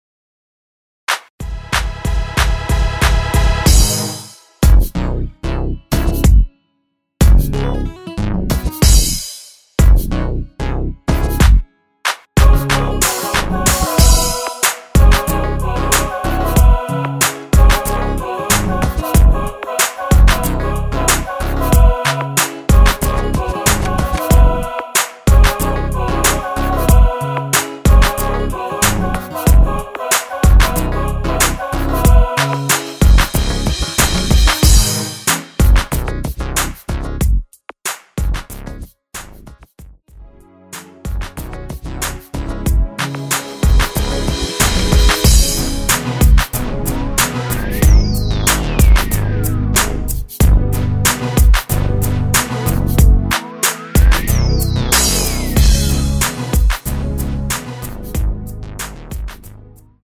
C#m
앞부분30초, 뒷부분30초씩 편집해서 올려 드리고 있습니다.
중간에 음이 끈어지고 다시 나오는 이유는